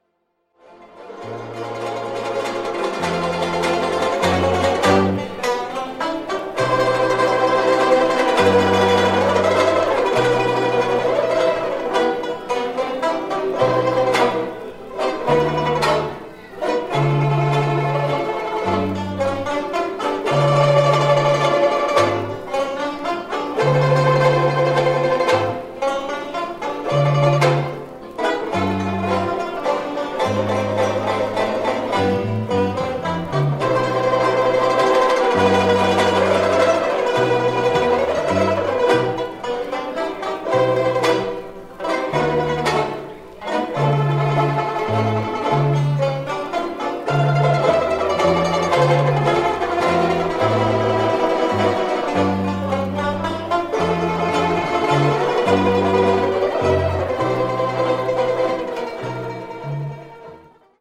6-beat intro.
This song is in 3/4 waltz time.
Listen to the Southern California Banjo Band perform "Fascination" (mp3)